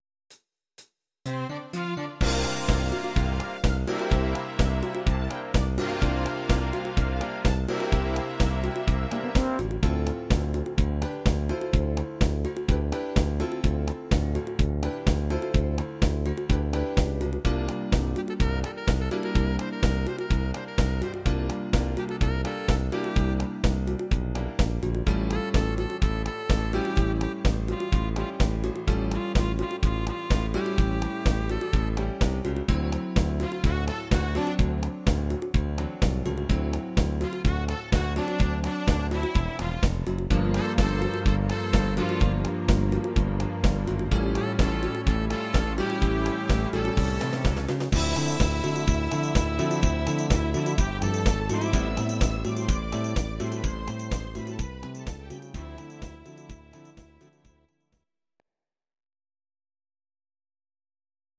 Please note: no vocals and no karaoke included.
Your-Mix: Disco (724)